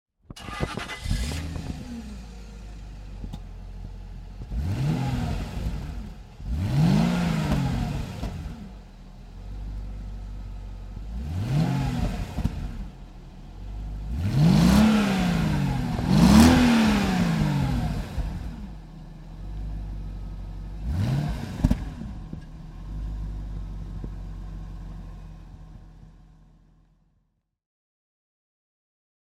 Jaguar XK8 Convertible (2000) - Starten und Leerlauf
Jaguar_XK8_2000.mp3